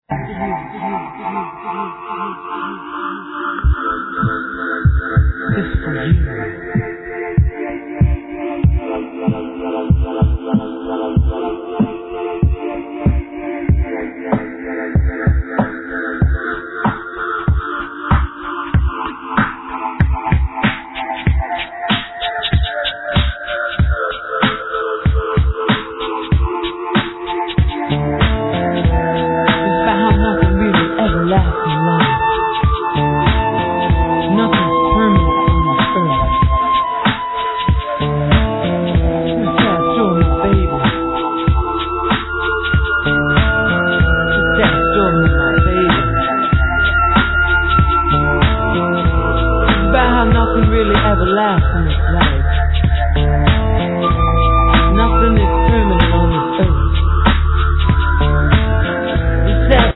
Lead vocals, Ocarina
Guitar, Vocals
Bass, Percussions, Didgeridoo
Saxophone
Flute